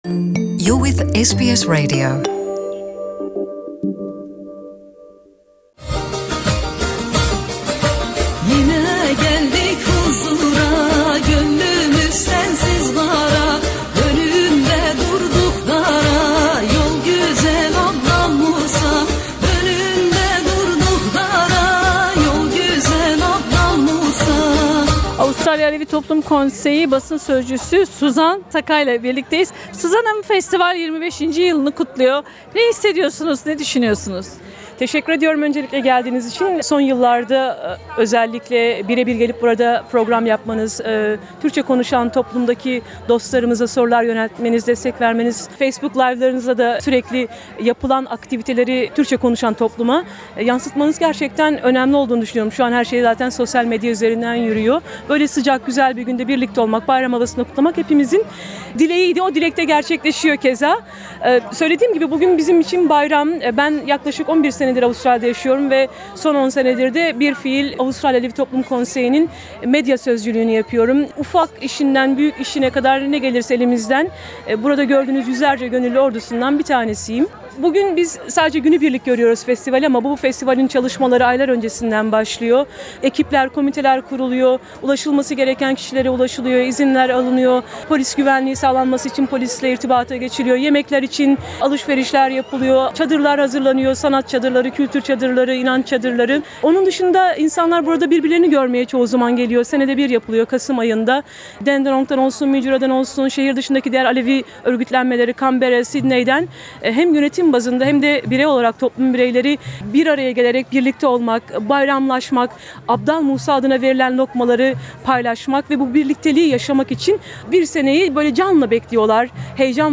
Avustralya Alevi Federasyonu'na bağlı Alevi derneklerince yapılan Anadolu Alevi Festivali'nin 25'nci yılı büyük bir coşkuyla kutlandı. Büyük bir katılımın olduğu festivalde toplum bireylerimize mikrofonlarımızı uzatarak, görüşlerini sorduk.